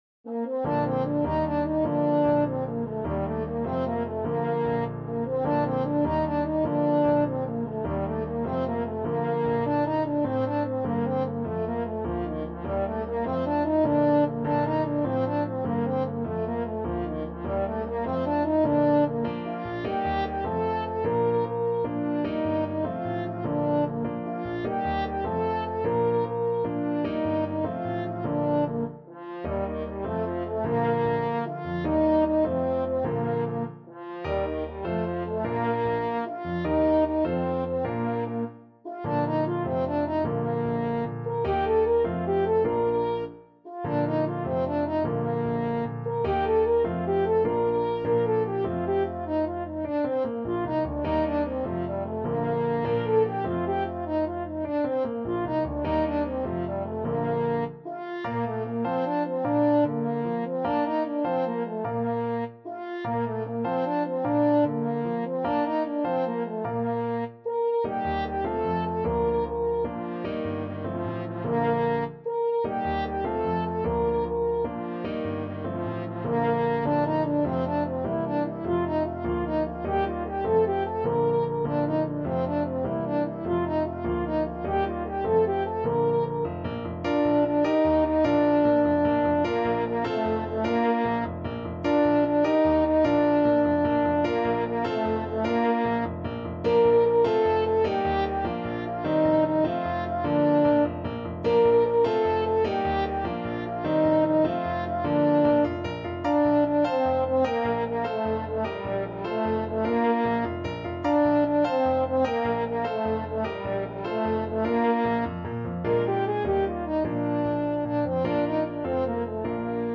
French Horn
Bb major (Sounding Pitch) F major (French Horn in F) (View more Bb major Music for French Horn )
6/8 (View more 6/8 Music)
Classical (View more Classical French Horn Music)